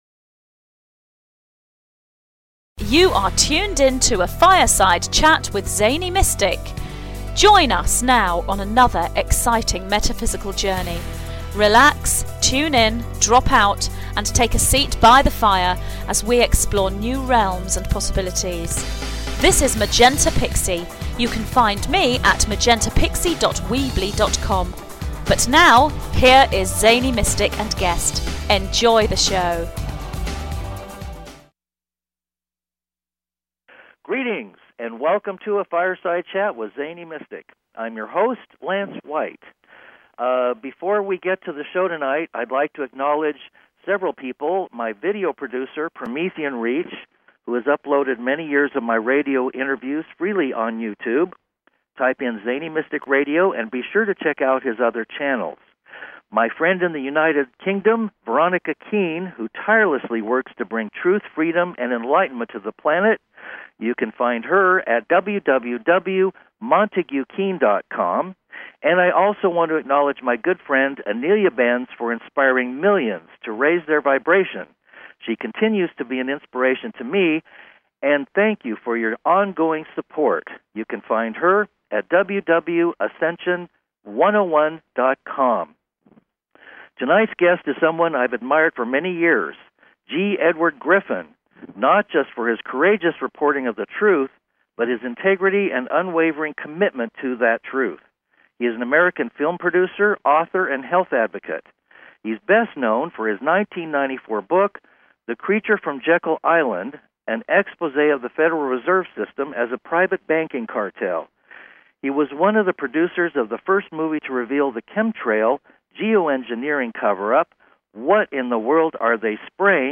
Guest, G. Edward Griffin